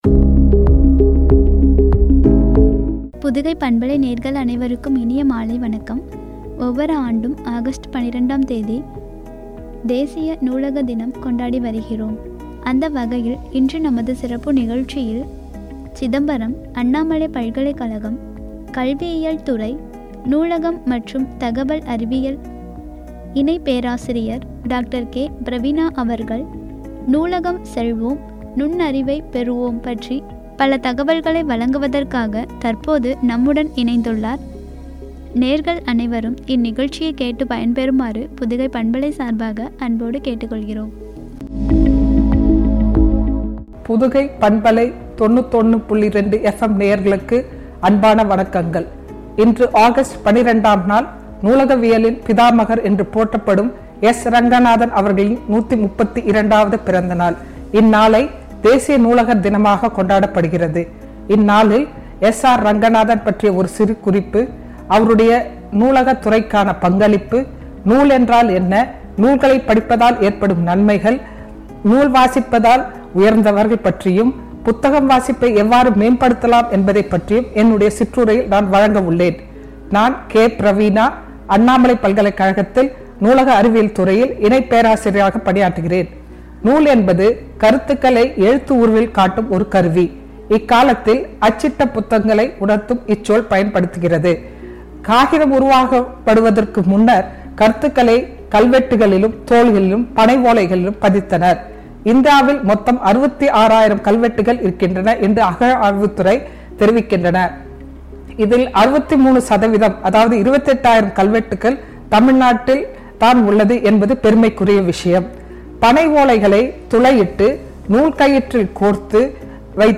நுண்ணறிவை பெறுவோம்” எனும் தலைப்பில் வழங்கிய உரையாடல்.